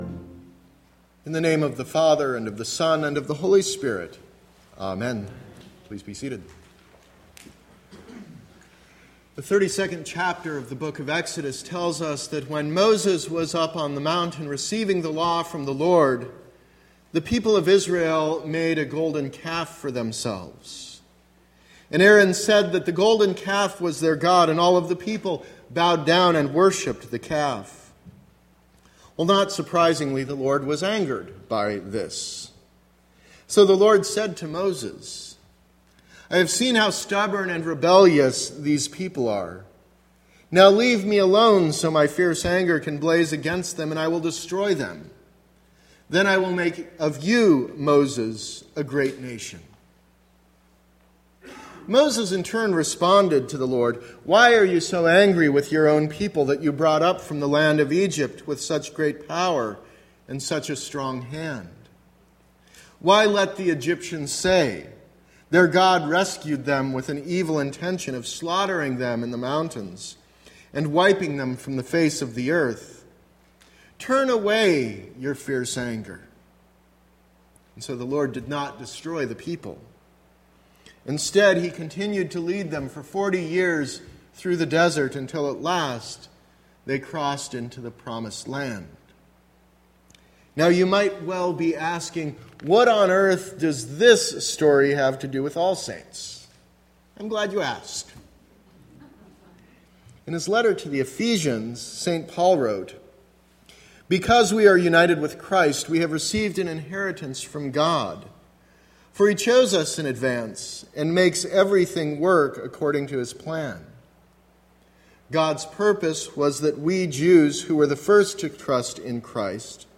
Sermon - October 30, 2016